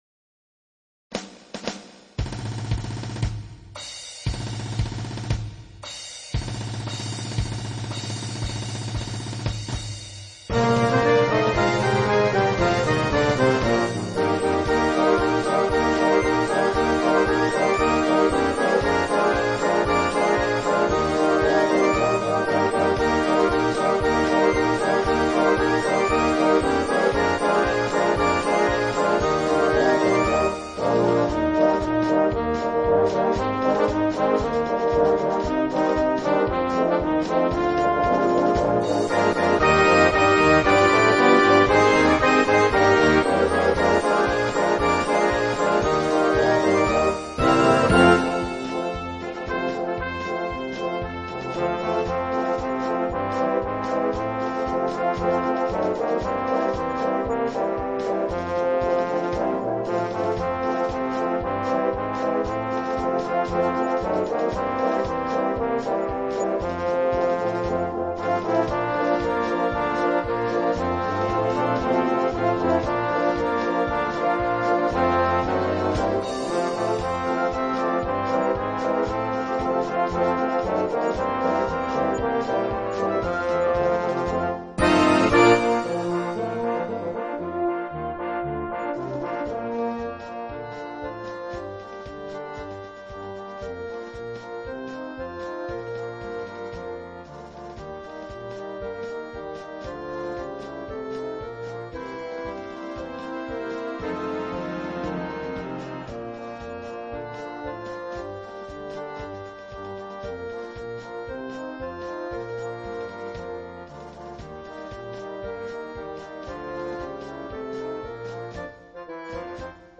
Voicing: Cnct Band